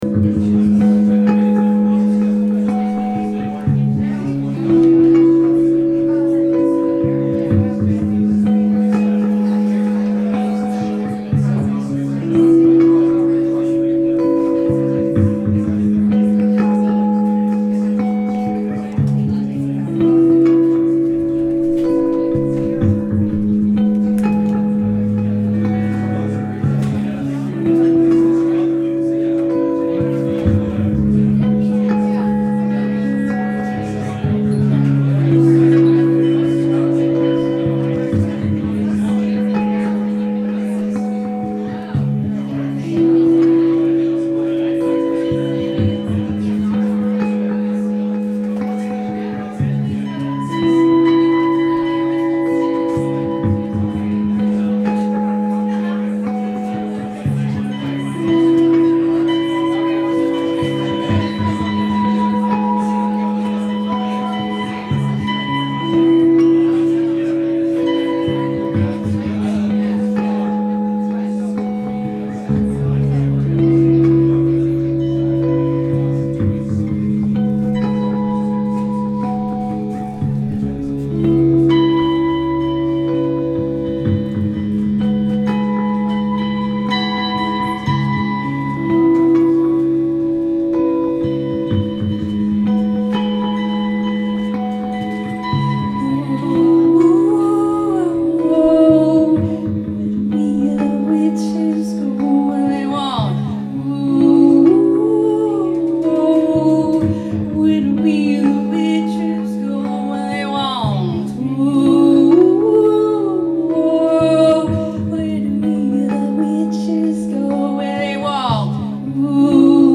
Live webstream for this, and most, shows at Spotty...